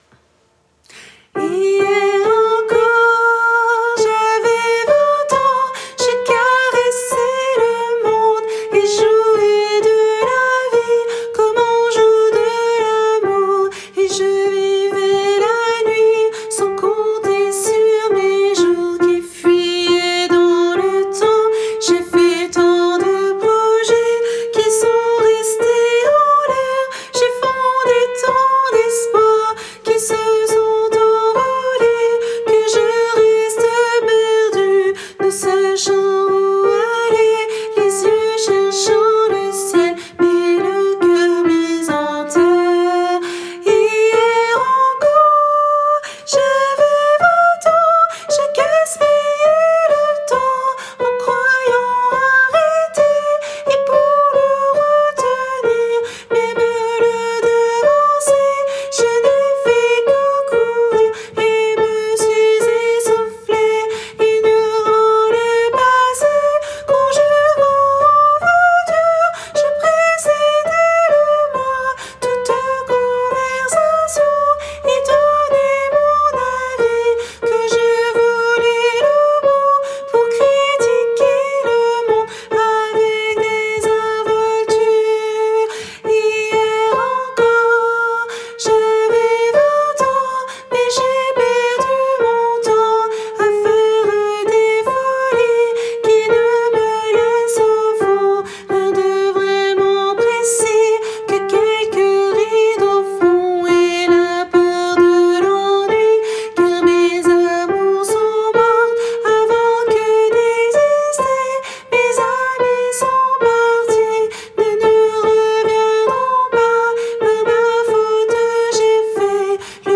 - Chant pour chœur mixte à 3 voix (SAH)
MP3 versions chantées
Soprano